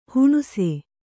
♪ huṇuse